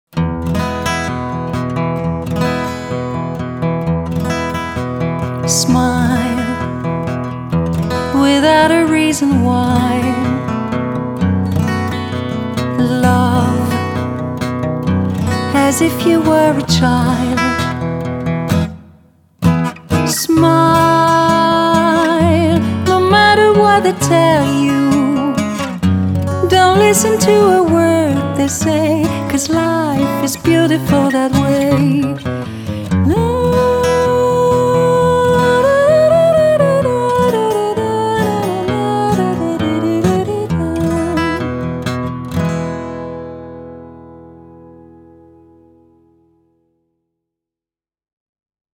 VOIX PARFUM ARMANI